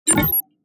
Stealth, Spy, Game Menu, Ui Confirm Sound Effect Download | Gfx Sounds
Stealth-spy-game-menu-ui-confirm.mp3